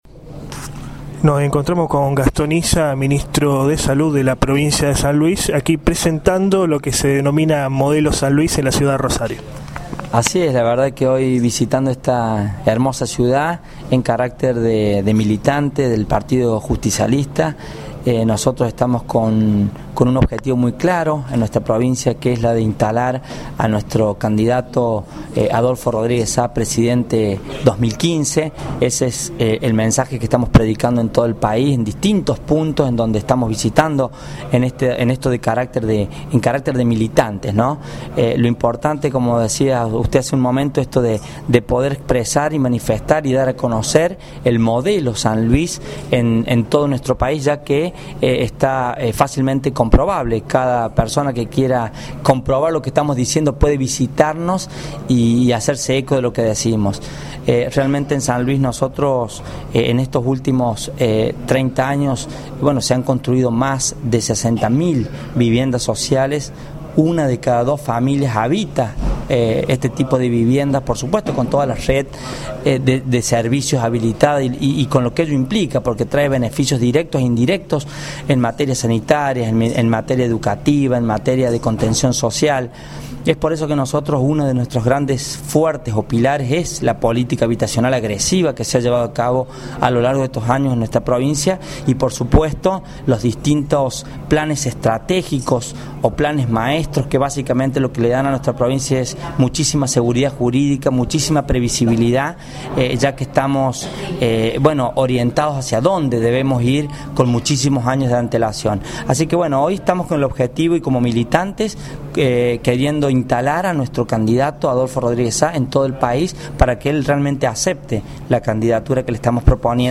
También, la semana pasada, estuvo presente en la ciudad cuna de la bandera, Gastón Hissa, Ministro de Salud del gobierno de Poggi.
GASTÓN HISSA AUDIO ENTREVISTA